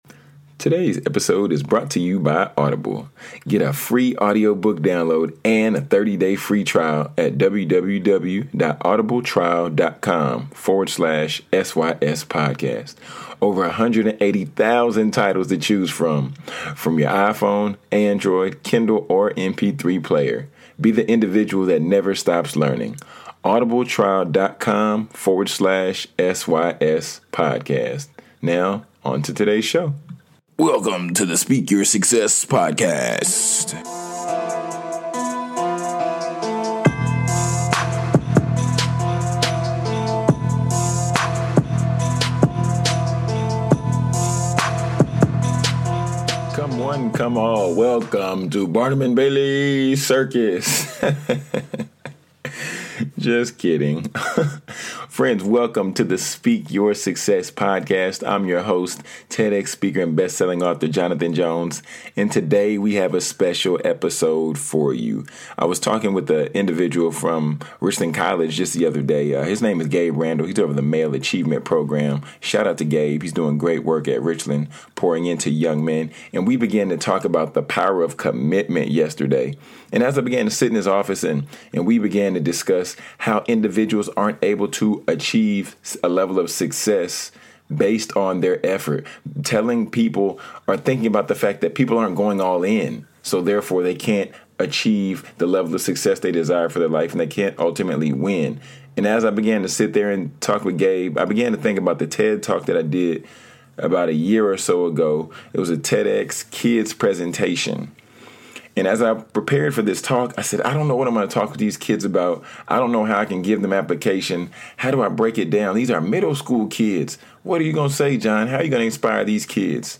EP 73 | The Power of Commitment (TEDx Presentation)